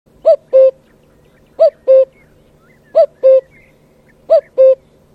05-kakukk.mp3